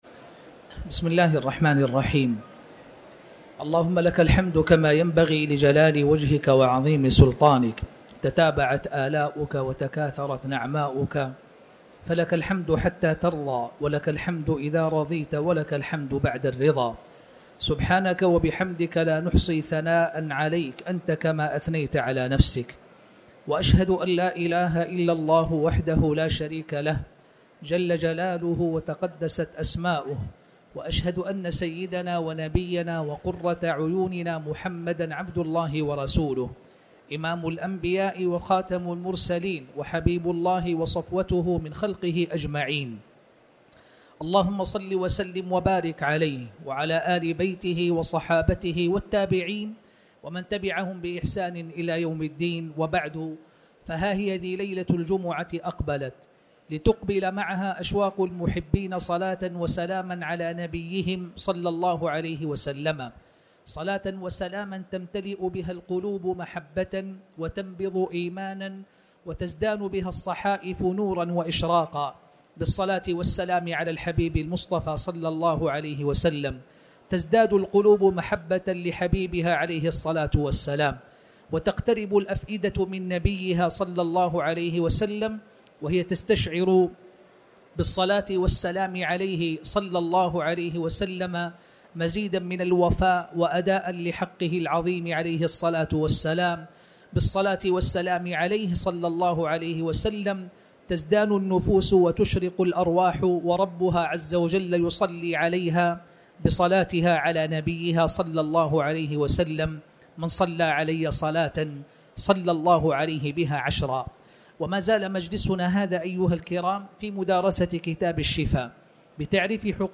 تاريخ النشر ٢٧ ربيع الثاني ١٤٤٠ هـ المكان: المسجد الحرام الشيخ